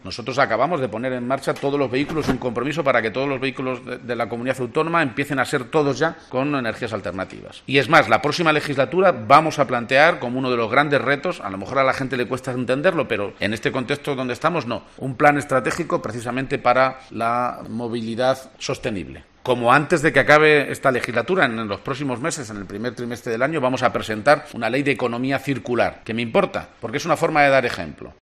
Emiliano García Page, inauguración gasinera Santa Cruz de Mudela